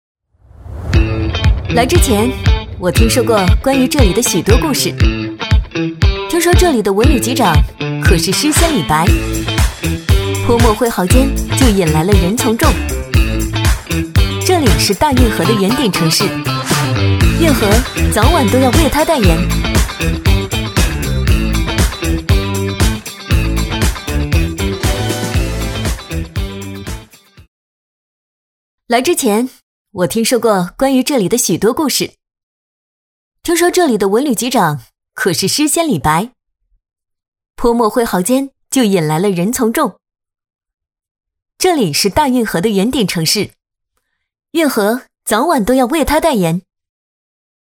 女B25-城市宣传片【青春向扬而行】 青春活力
女B25-城市宣传片【青春向扬而行】 青春活力.mp3